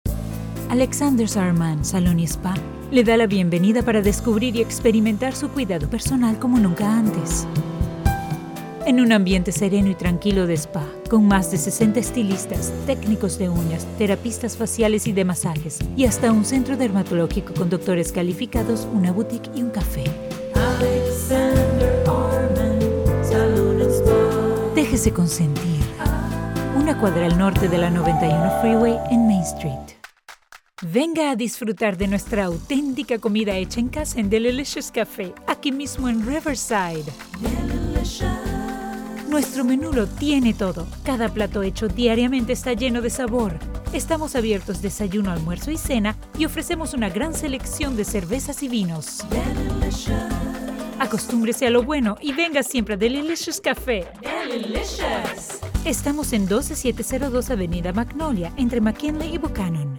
My accent is Neutral / Latino / Mexican / Venezuelan.
I have a deep, warm voice with a captivating very persuasive warm tone that gathers attention.
Sprechprobe: Werbung (Muttersprache):